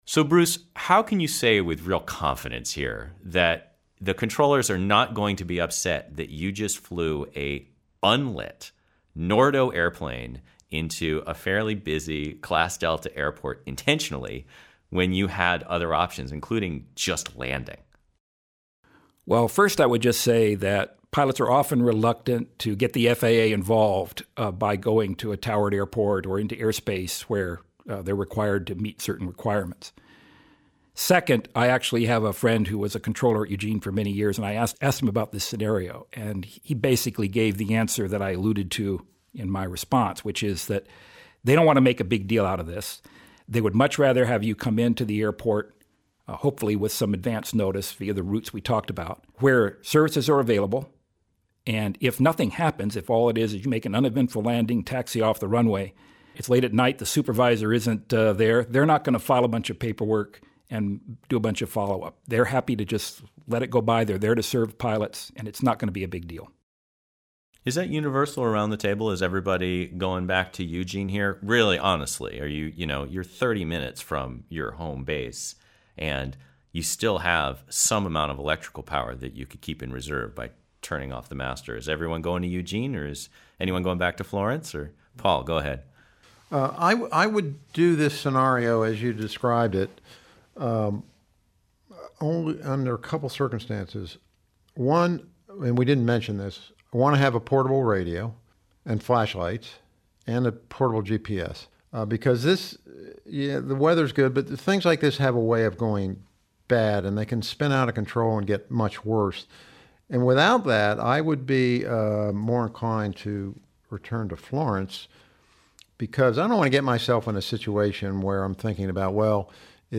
Low_Volts_over_Eugene_roundtable.mp3